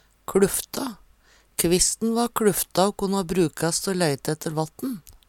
kLuffta - Numedalsmål (en-US)